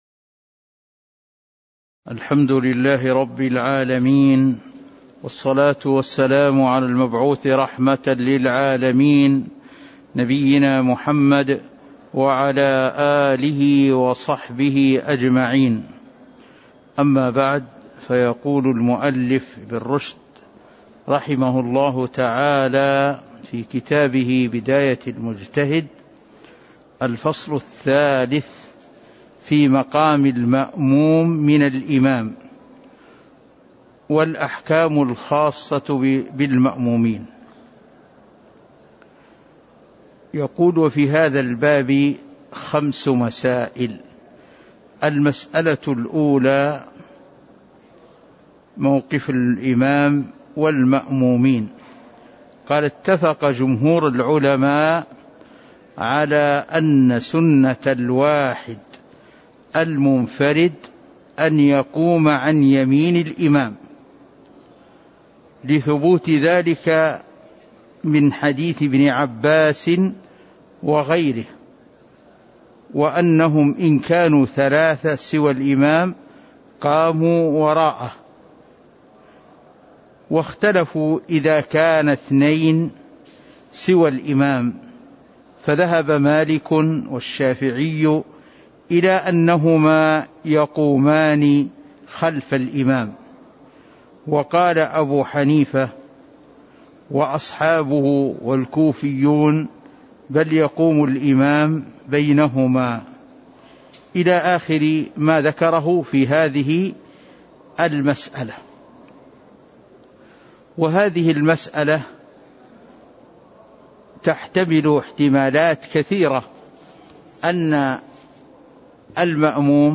تاريخ النشر ١٨ ربيع الأول ١٤٤٣ هـ المكان: المسجد النبوي الشيخ